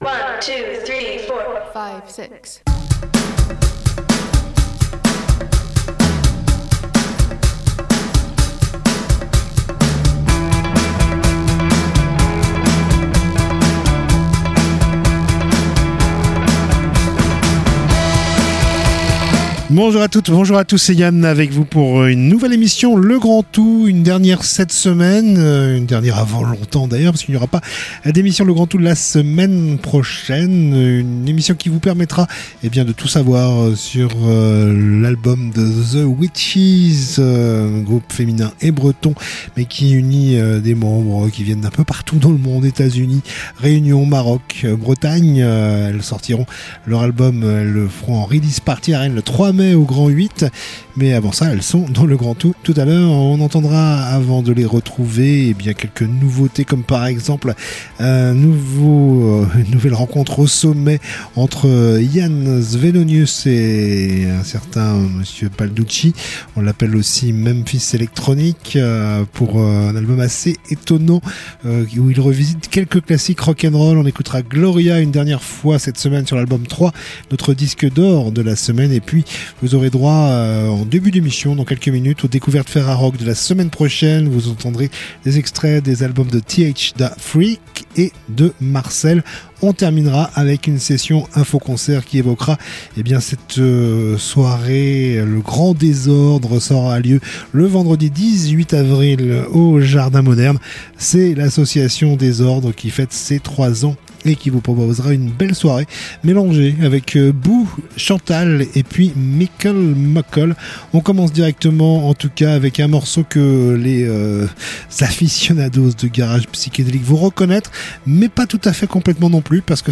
Discussion avec 3 des 4 musiciennes de ZeWitches à propos de leur album Faces